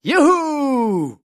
voz nș 0150